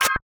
UIMisc_Tonal Short 02.wav